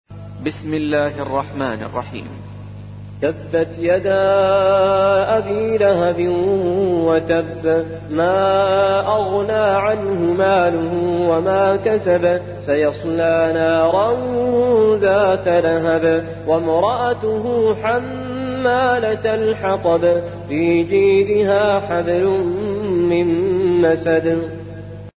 111. سورة المسد / القارئ